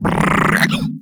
taunt1.wav